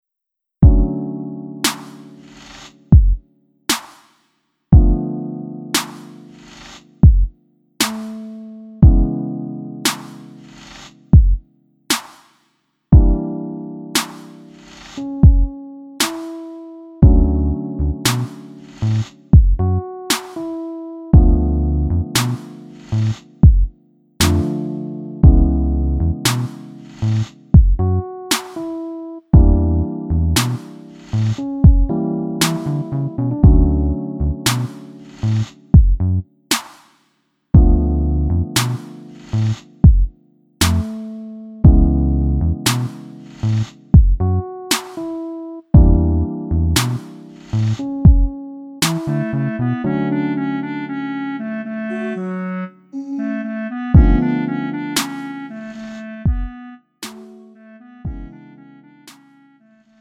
음정 -1키 4:59
장르 구분 Lite MR